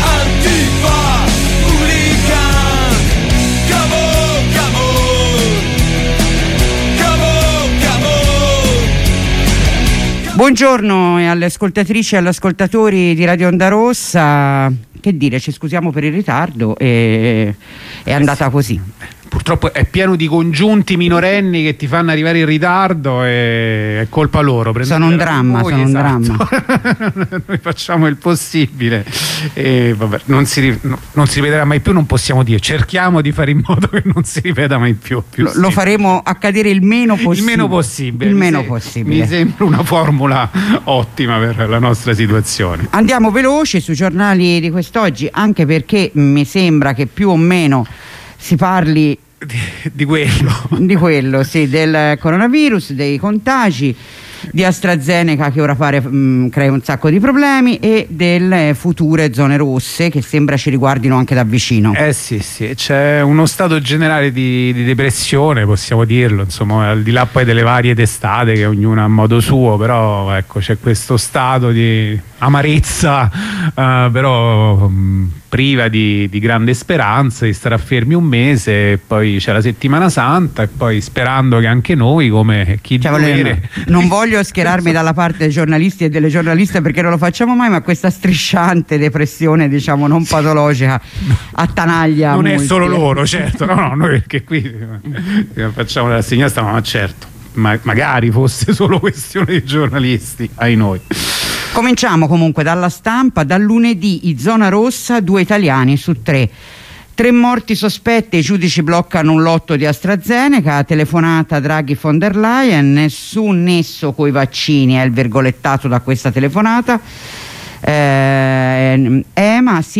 Lettura dei giornali